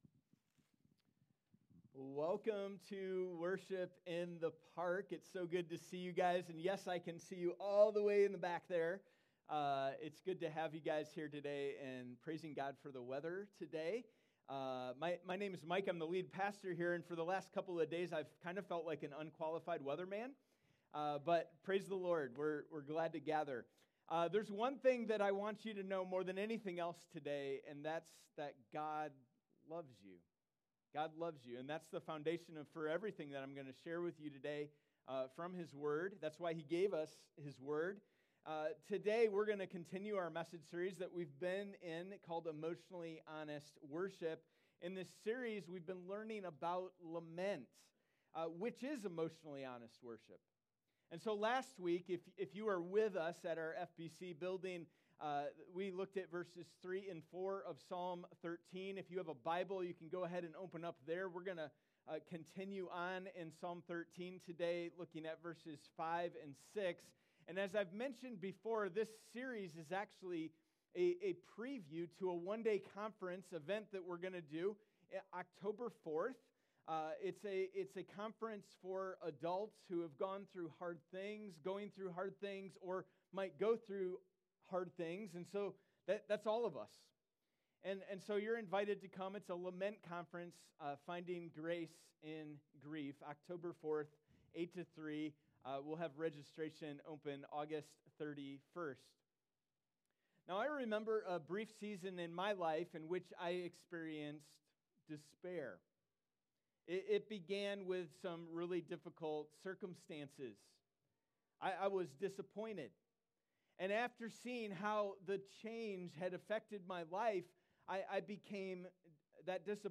Worship in the Park: Psalm 13:5-6 | HOPE